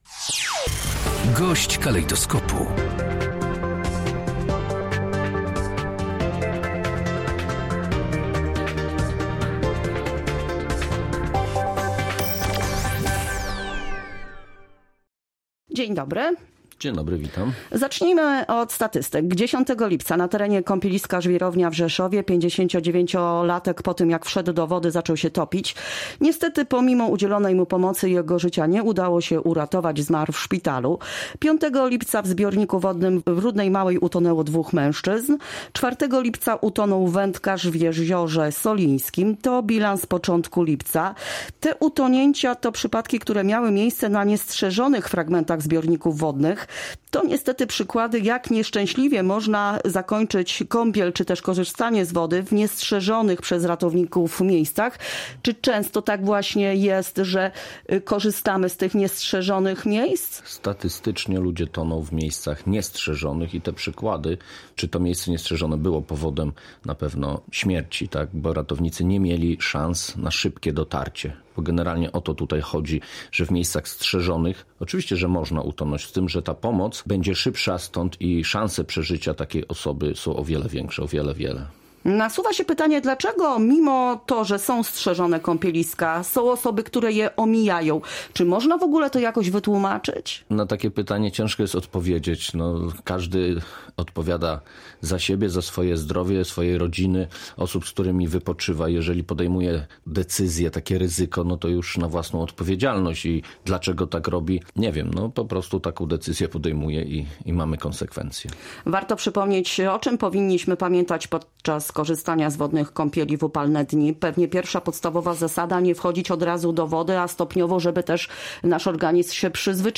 GOŚĆ DNIA: Najczęściej ludzie toną w miejscach niestrzeżonych • Audycje • Polskie Radio Rzeszów